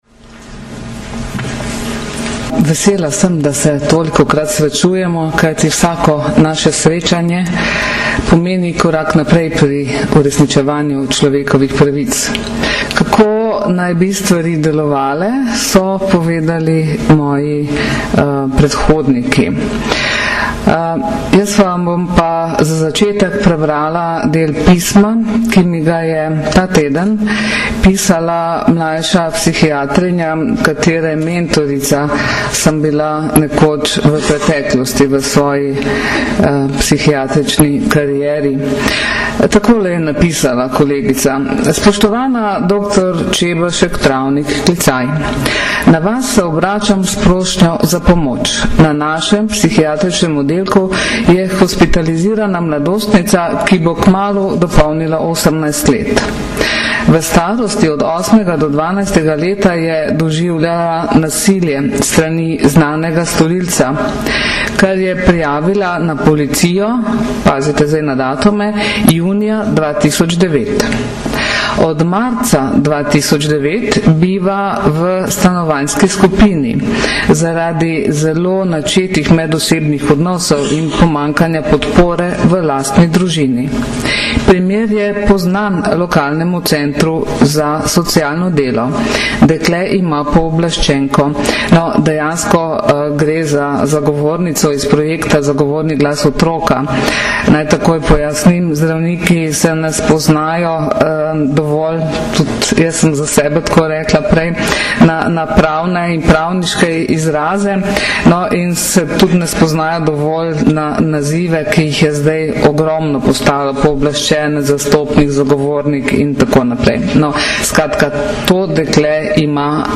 Danes, 9. aprila 2010, se je zaključil dvodnevni posvet z naslovom Nasilje nad otroki - razumeti in prepoznati, ki sta ga na Brdu pri Kranju organizirala Generalna policijska uprava in Društvo državnih tožilcev Slovenije.
Zvočni posnetek govora varuhinje človekovih pravic dr. Zdenke Čebašek Travnik (mp3)